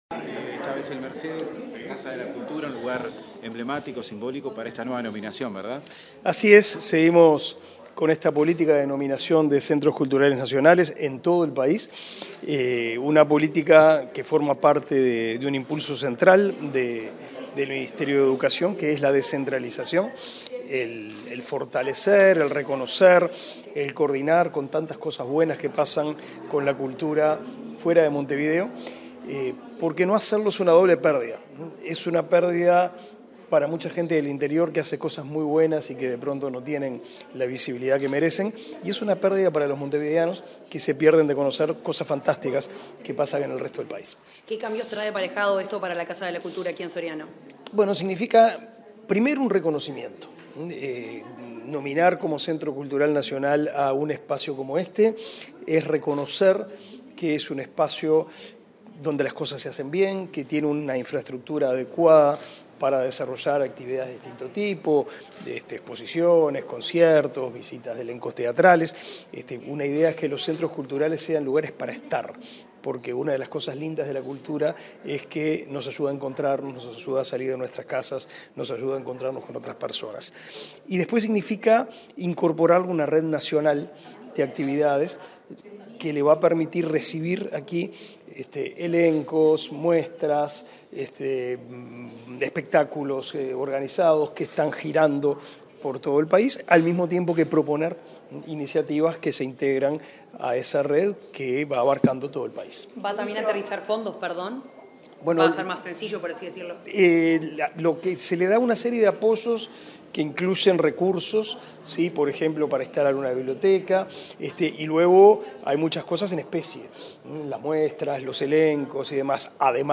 Declaraciones del ministro de Educación y Cultura, Pablo da Silveira
Declaraciones del ministro de Educación y Cultura, Pablo da Silveira 17/05/2023 Compartir Facebook X Copiar enlace WhatsApp LinkedIn Tras el acto de nominación de la Casa de la Cultura de Mercedes como Centro Cultural Nacional, este 17 de mayo, el ministro de Educación y Cultura, Pablo da Silveira, realizó declaraciones a la prensa.